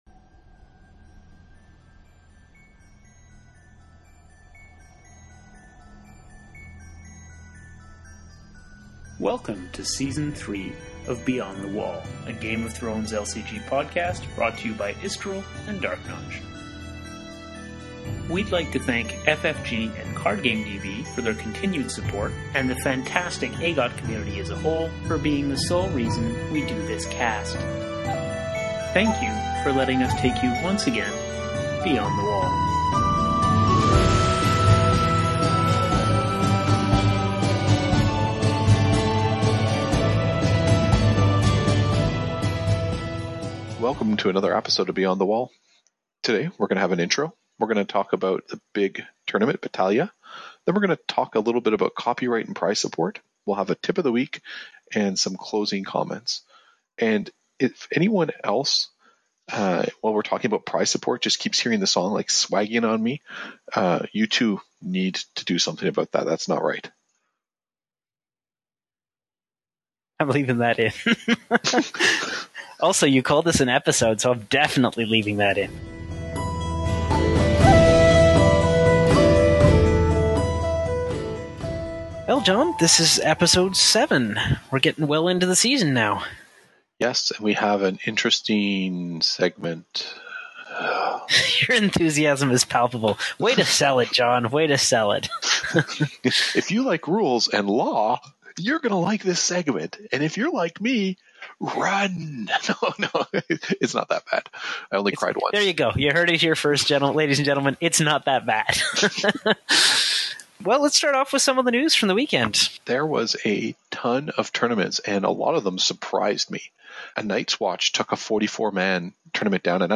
First, we (too) had a number of technical issues with our recording.